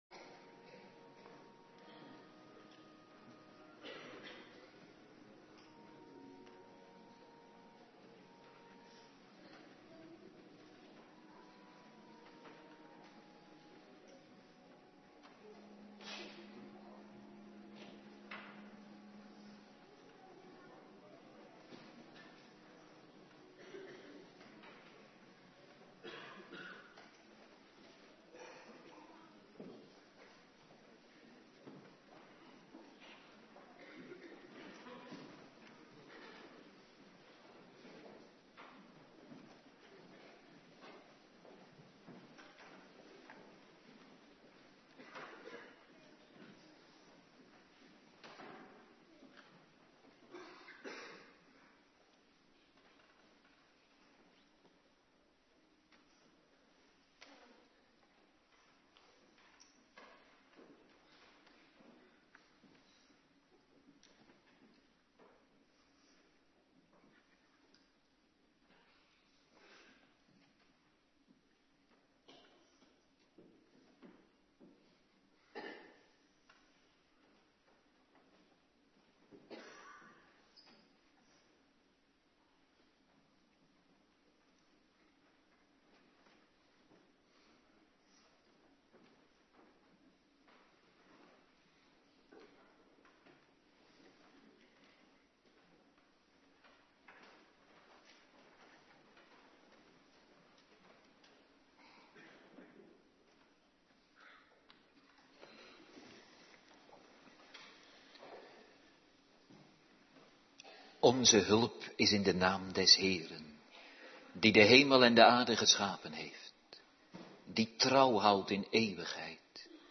Morgendienst
09:30 t/m 11:00 Locatie: Hervormde Gemeente Waarder Agenda